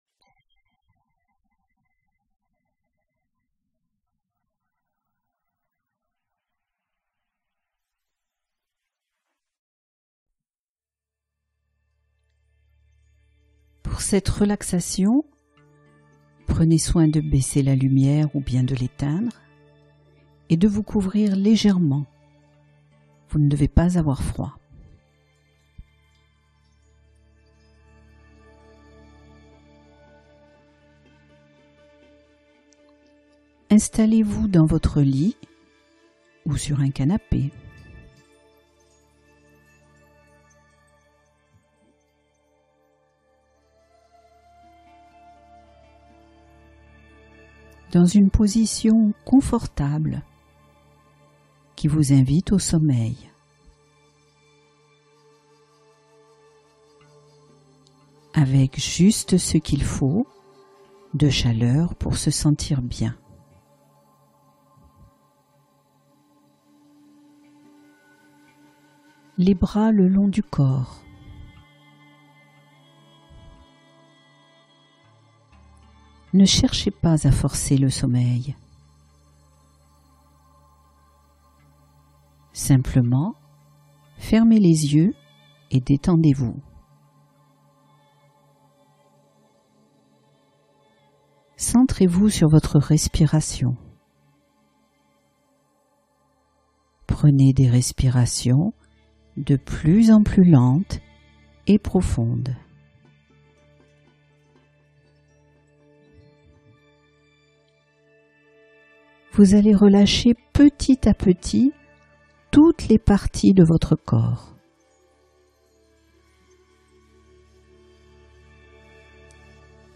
Migraines apaisées par la détente mentale — Relaxation ciblée